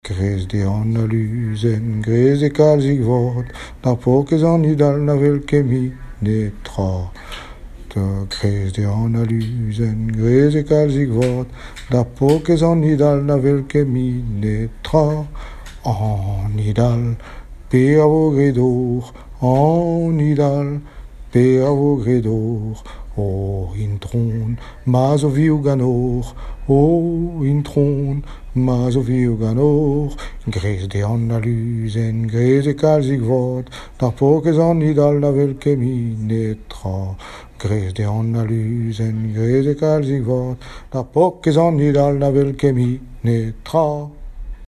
Chansons populaires
Pièce musicale inédite